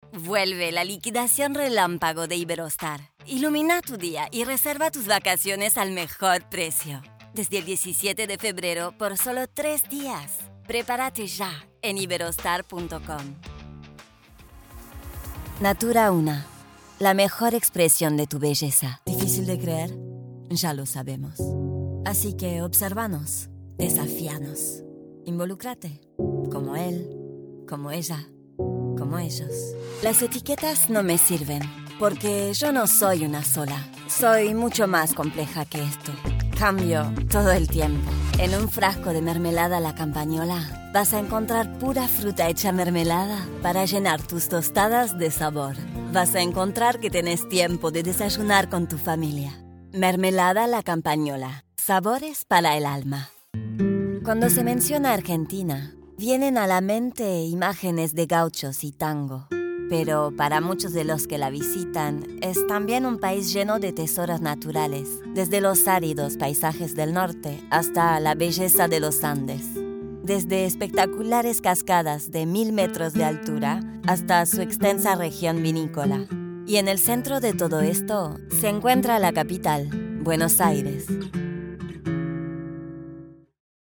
Commercial Demo
I can also perform in English with a more or less pronounced French accent, as well as Spanish with either South American or neutral accents.
Home Studio Setup
Microphone : RODE NT1-A